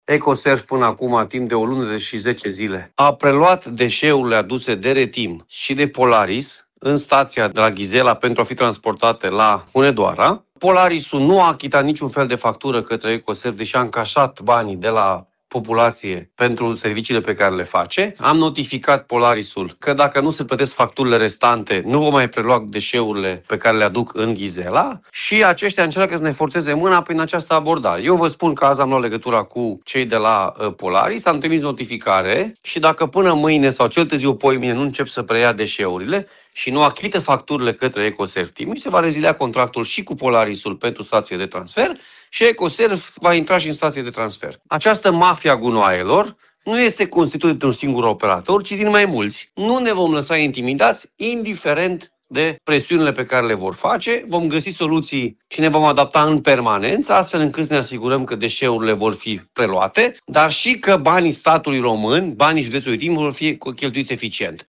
Președintele Consiliului Județean Timiș, Alfred Simonis, susține că Polaris nu a achitat către Ecoserv facturile pentru deșeurile trimise la Hunedoara, iar dacă banii nu sunt plătiți se trece la rezilierea contractului.
h21-Alfred-Simonis-suspendare-deseuri.mp3